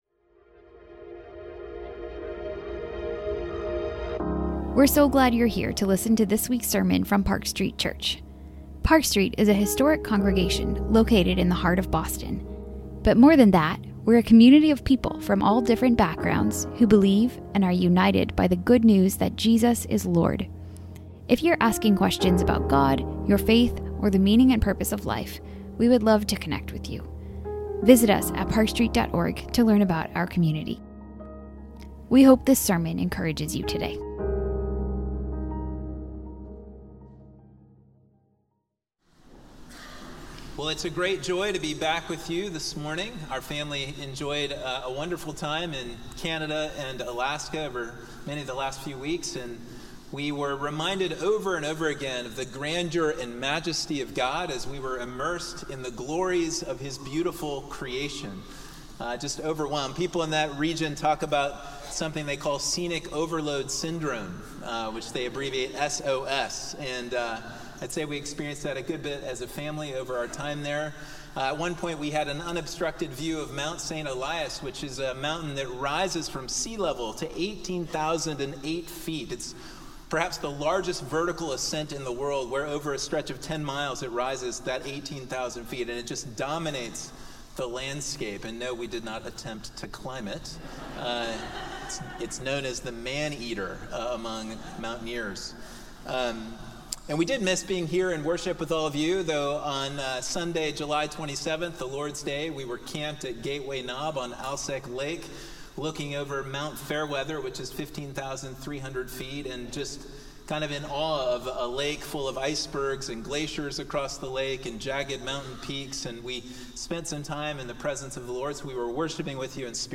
This sermon considers how God's ultimate deliverance through Christ enables us to live with hope and thanksgiving even in our darkest moments.